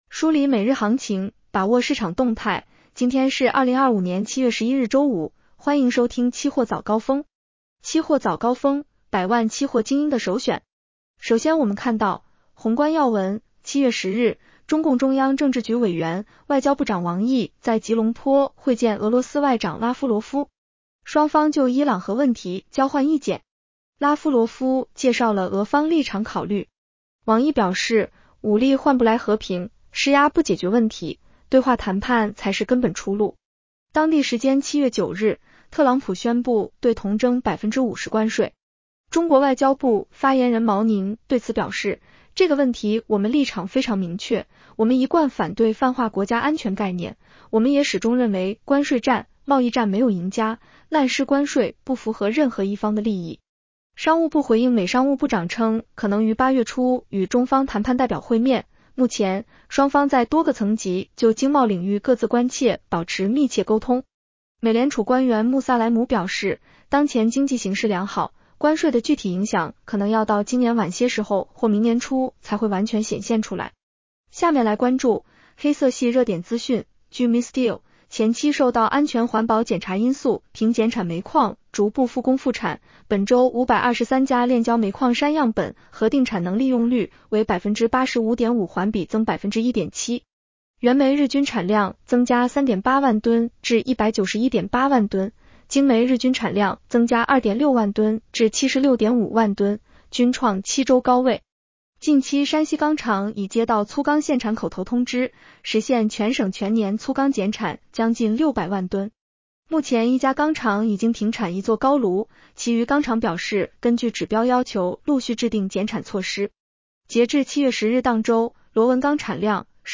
期货早高峰-音频版 女声普通话版 下载mp3 宏观要闻 1. 7月10日，中共中央政治局委员、外交部长王毅在吉隆坡会见俄罗斯外长拉夫罗夫。